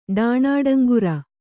♪ ḍāṇāḍaŋgura